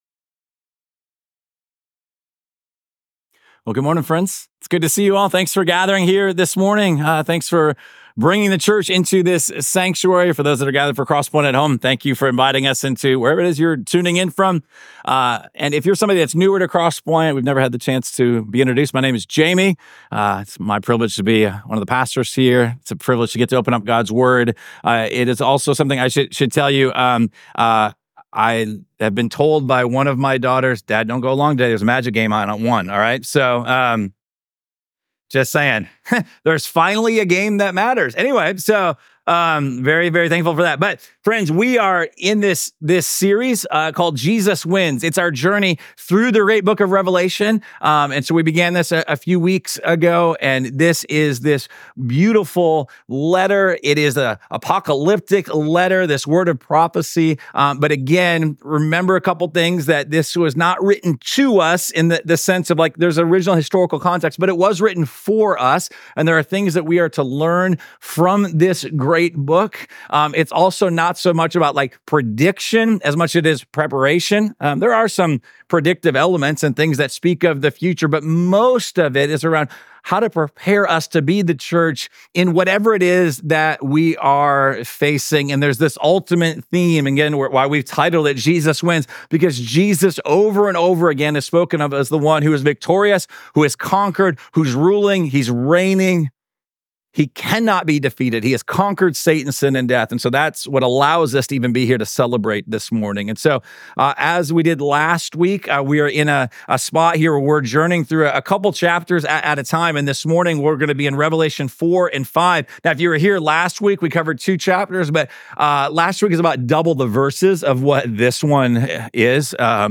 This sermon comes from Revelation chapters 4-5